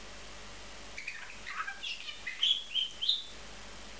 Wellensittich Songs: Musik für unsere Ohren
Hier jetzt einige "Songs" von unseren Sittichen, beim Schlummerstündchen und während des Spielens aufgenommen.
tschirp8.wav